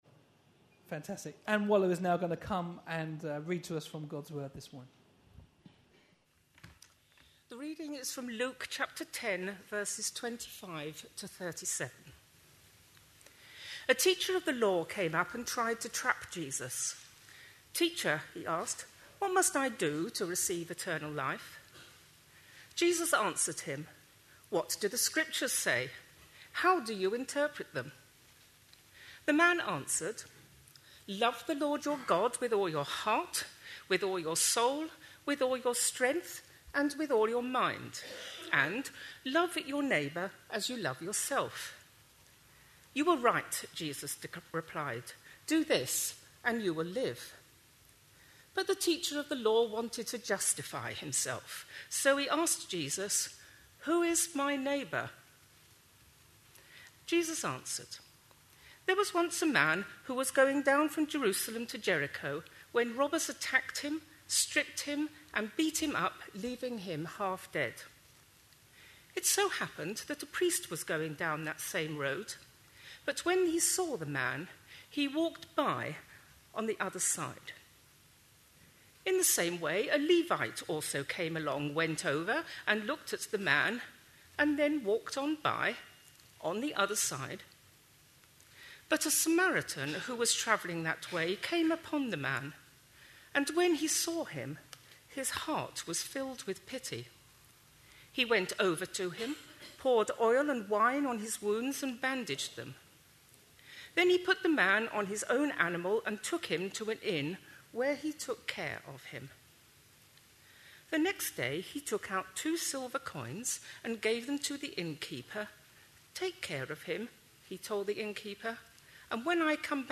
A sermon preached on 23rd October, 2011, as part of our The Beatitudes. series.